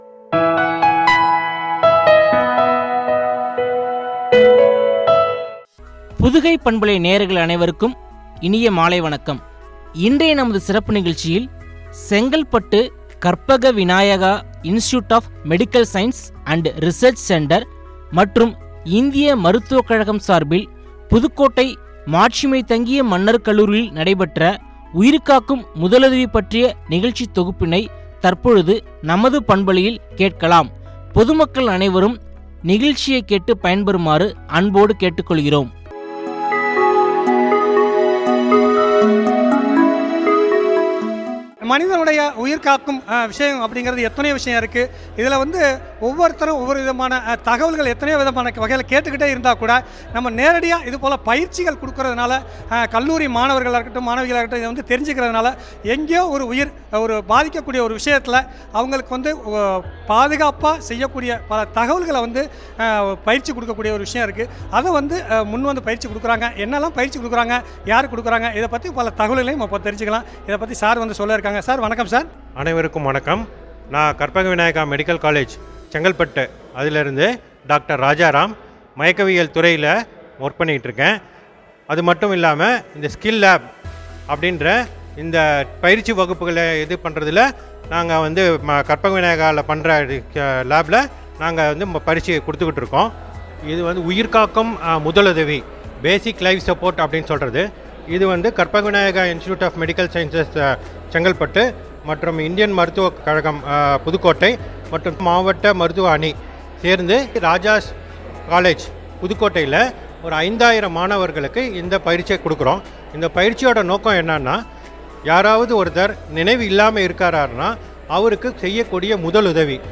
புதுக்கோட்டை மா.மன்னர் கல்லூரியில் நடைபெற்ற உயிர்காக்கும் முதலுதவி பற்றிய நிகழ்ச்சி தொகுப்பு.